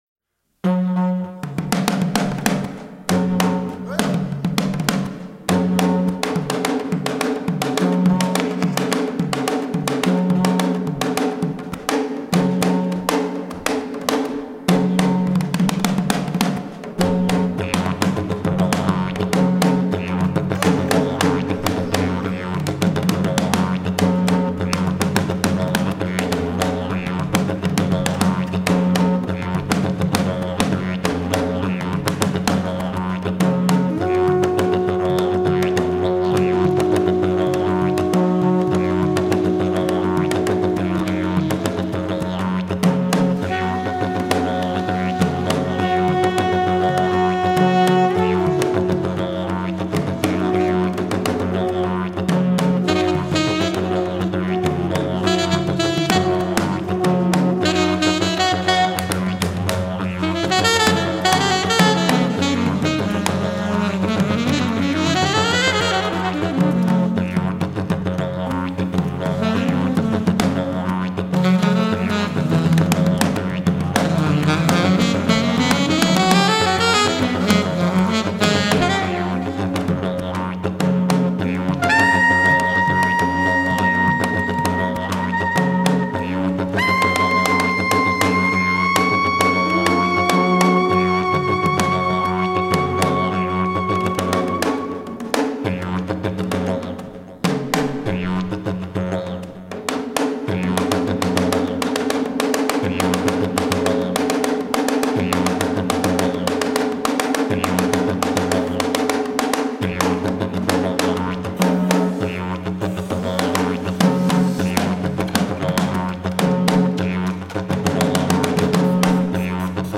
( Didjeridoo, percussioni africane e conchighie).